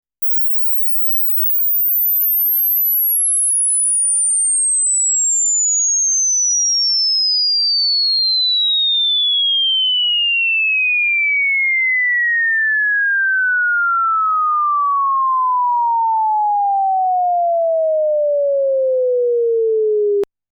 → レベルメーターで-12dBぴったりになるように録音したスウィープ信号
可聴域上限の20kHzから400Hzに20秒かけて降りてくるサイン波なので
高い音からフェードインするように聴こえるはず．
-12dBでは正しく録音できている．